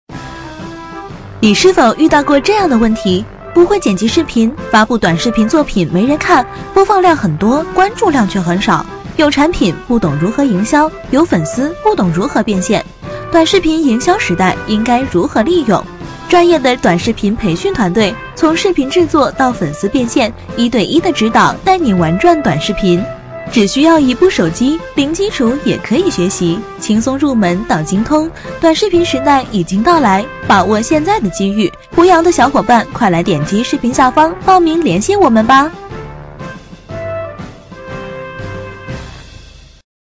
促销女配
特价女31|【女31号抖音视频配音】你是否遇到过这样的问题
【女31号抖音视频配音】你是否遇到过这样的问题.mp3